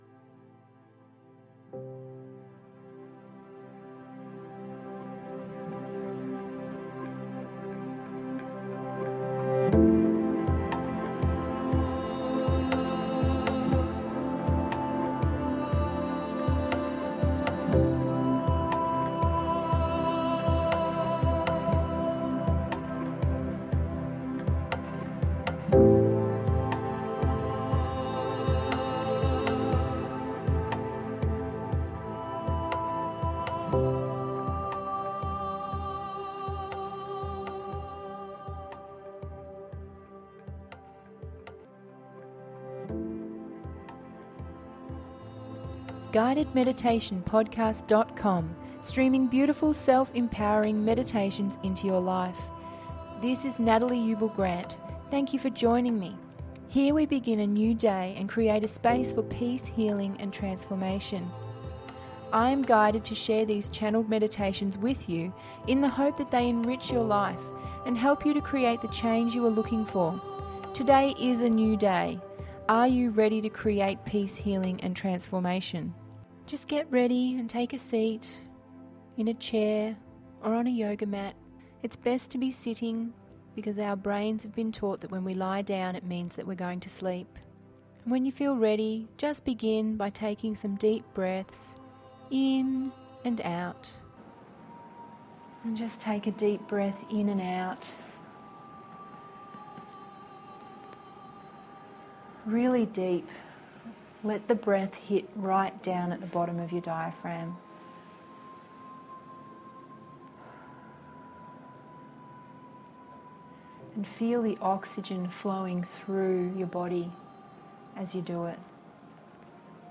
Consult Your Shadow…053 – GUIDED MEDITATION PODCAST